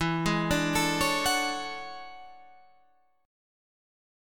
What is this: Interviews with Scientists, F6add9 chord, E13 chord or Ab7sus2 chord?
E13 chord